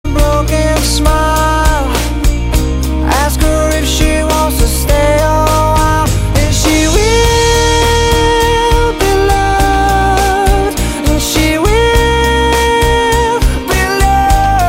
• Category Pop